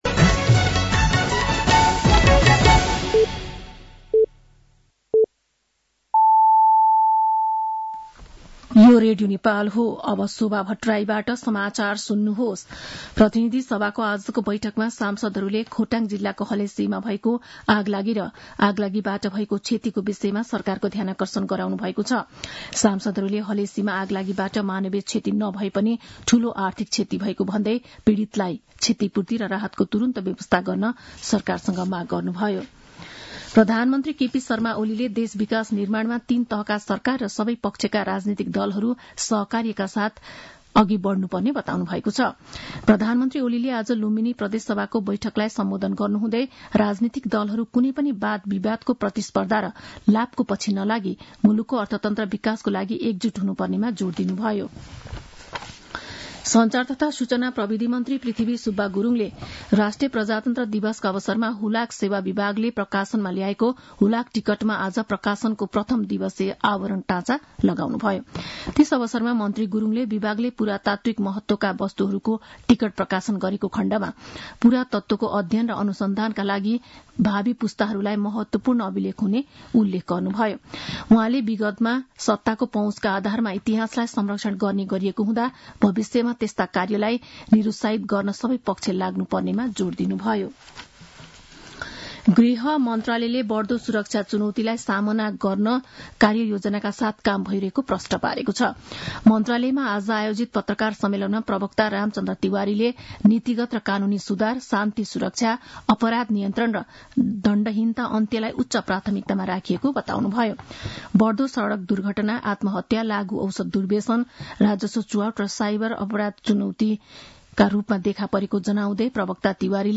साँझ ५ बजेको नेपाली समाचार : ६ फागुन , २०८१
5-pm-nepali-news-11-05.mp3